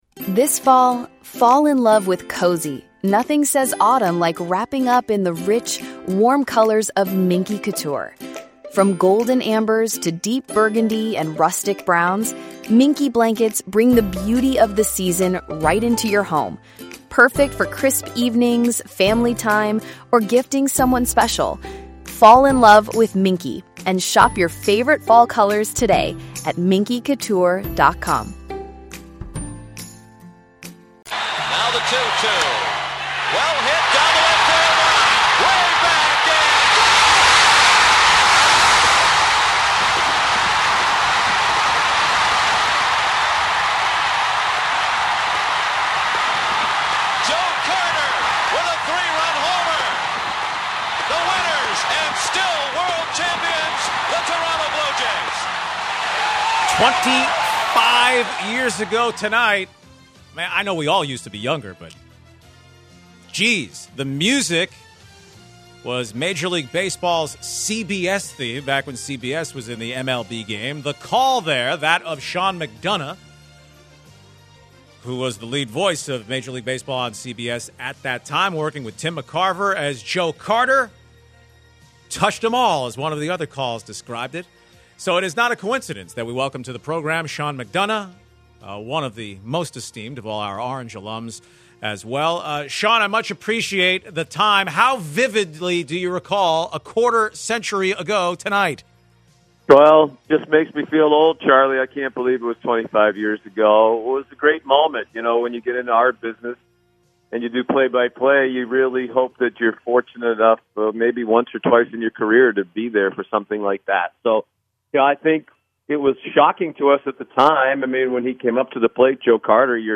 10/23/2018 Sean McDonough Interview